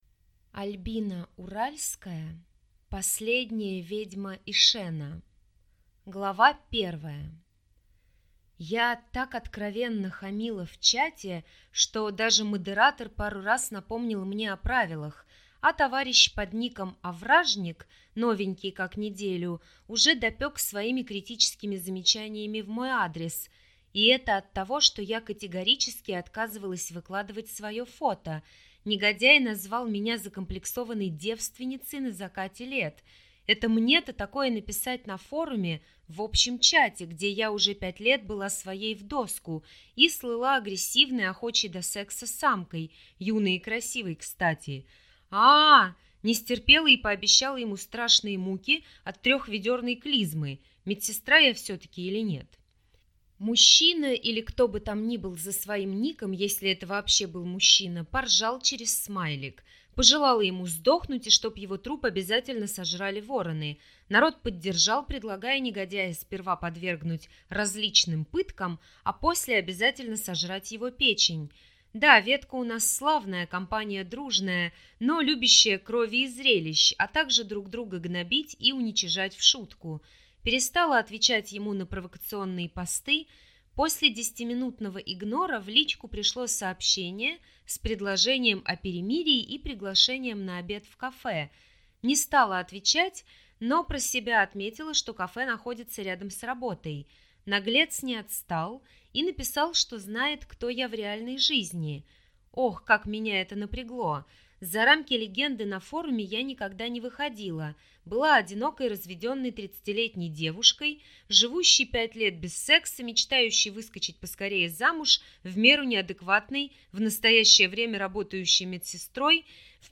Аудиокнига Последняя ведьма Ишэна | Библиотека аудиокниг